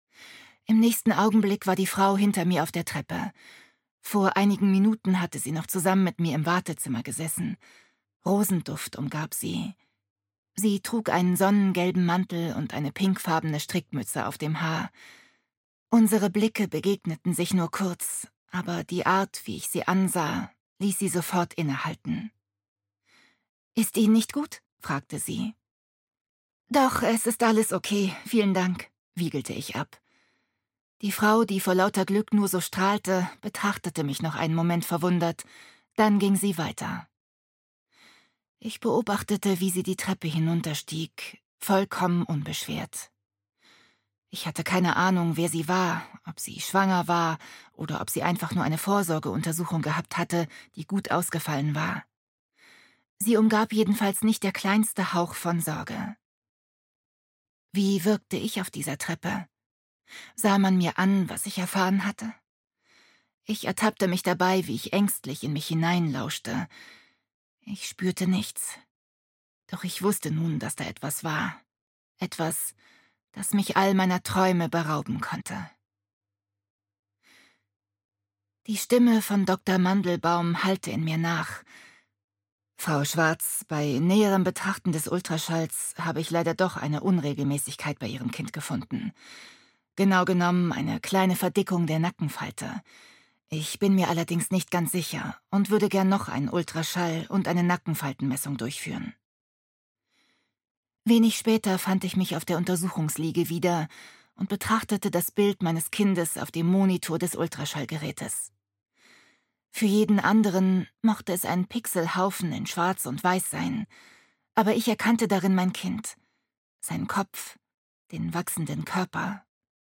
Das Mohnblütenjahr - Corina Bomann - Hörbuch